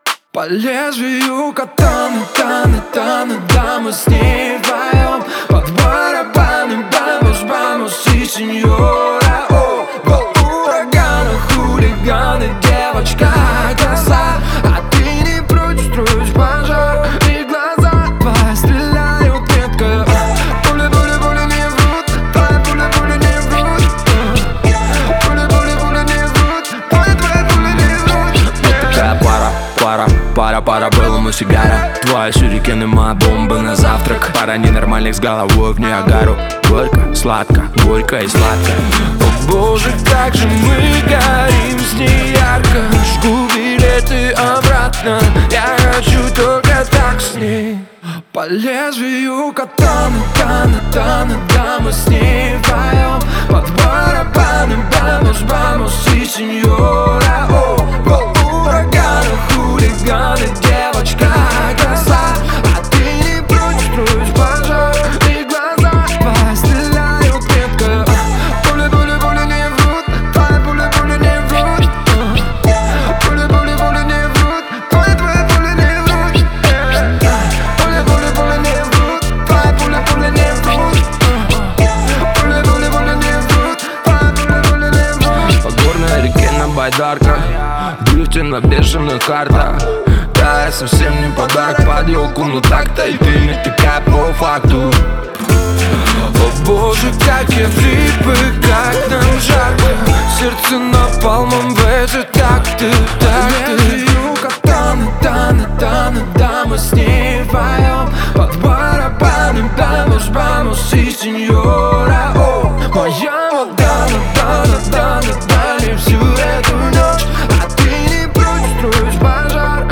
Настроение трека — меланхоличное, но с надеждой на лучшее.
мощные гитарные рифы
выразительный вокал